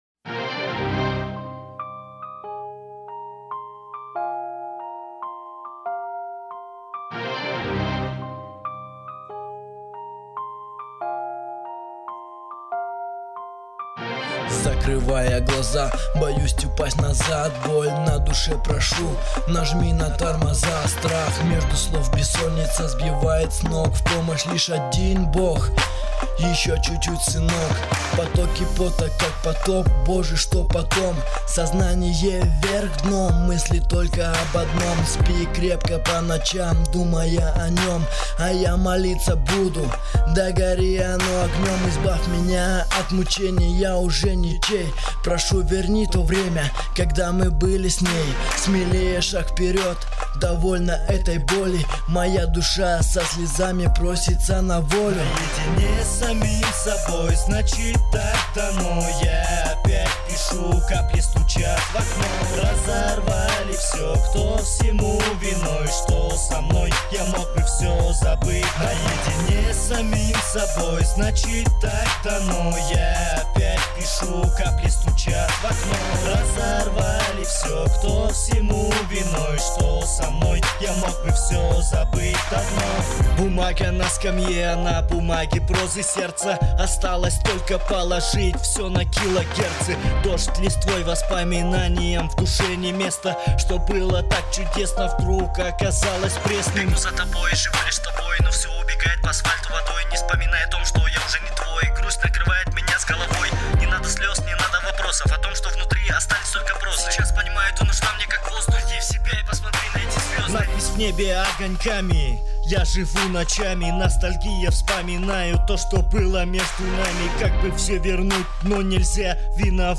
Музыкальный хостинг: /Рэп